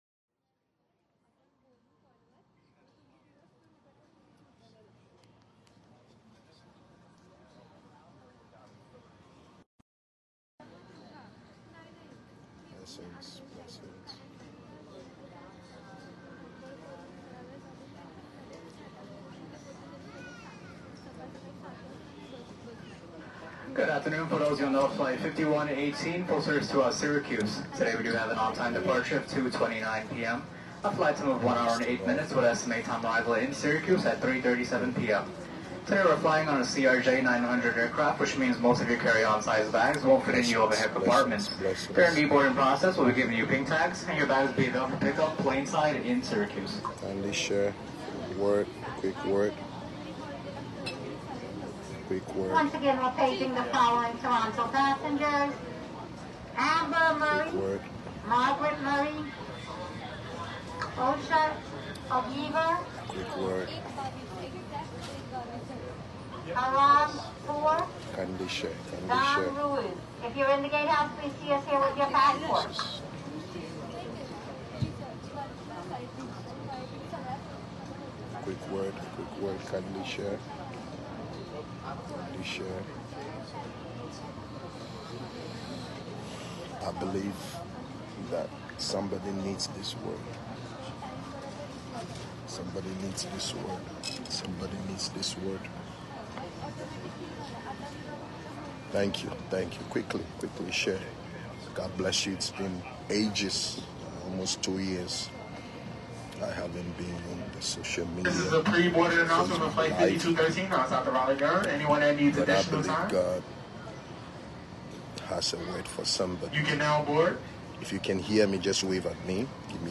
This quick word was ministered live on Facebook as the Prophet of God was at the JFK Airport in New York, USA.